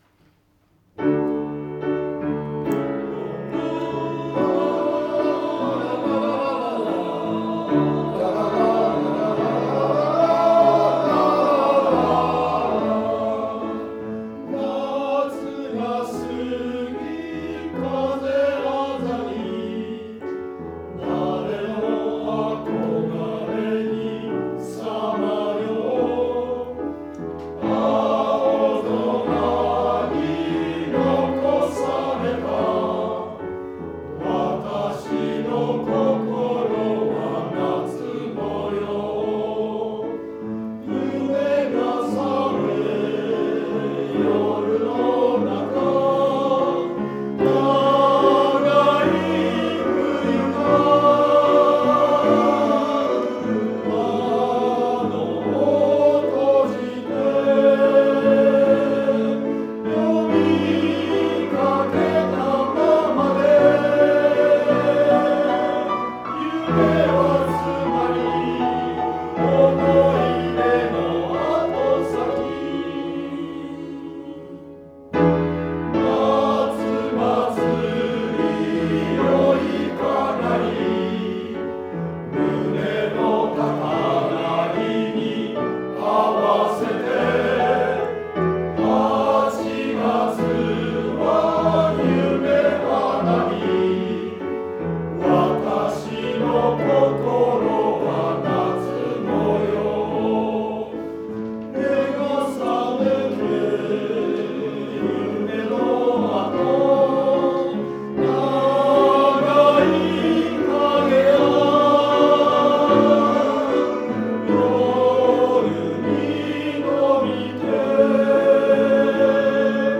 「少年時代」「高原列車は行く」 の練習
本日の練習録音を以下のフォルダに保管しました。
今日の練習でかなり改善しましたが、まだ徹底でていません。
とにかくテンポが遅れないように。
まだ油断して遅れる箇所あり。